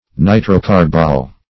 nitrocarbol - definition of nitrocarbol - synonyms, pronunciation, spelling from Free Dictionary
Nitrocarbol \Ni`tro*car"bol\, n. [Nitro- + carbon + L. oleum